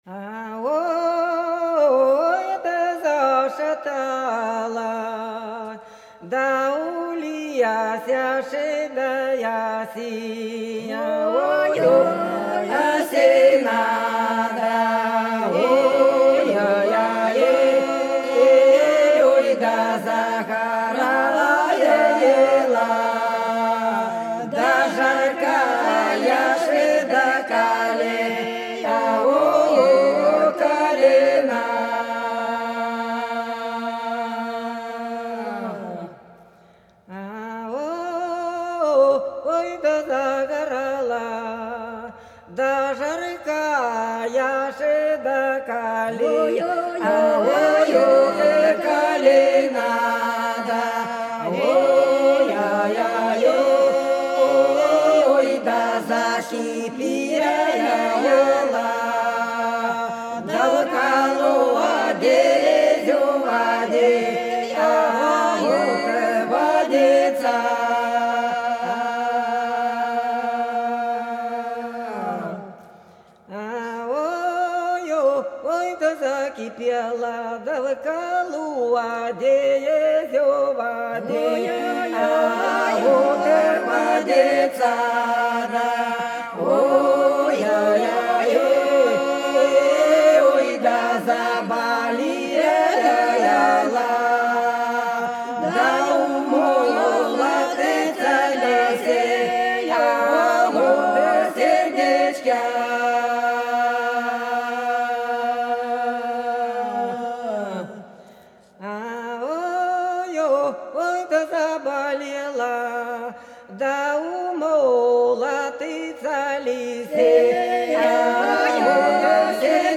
Белгородские поля (Поют народные исполнители села Прудки Красногвардейского района Белгородской области) Ой, да зашаталась у лесе осина - протяжная